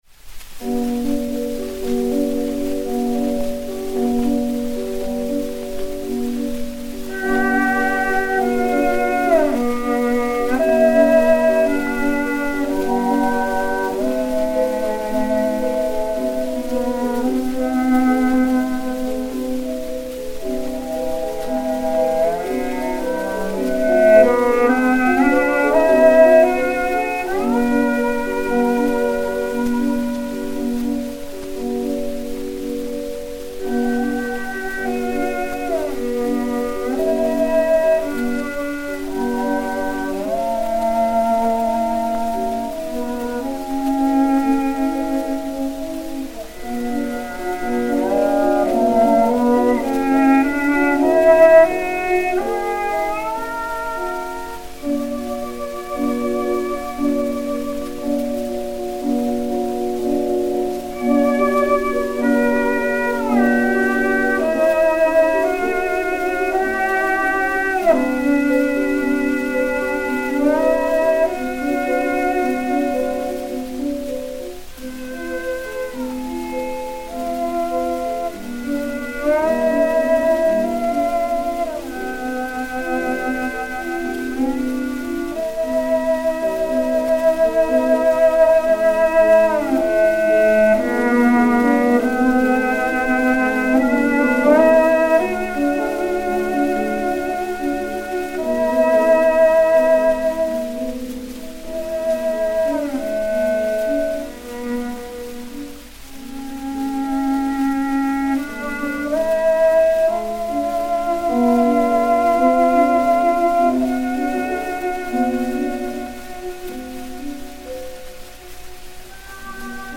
William Henry Squire (violoncelle) et Hamilton Harty (piano)
Disque Pour Gramophone W 292, mat. 078072, enr. le 12 février 1913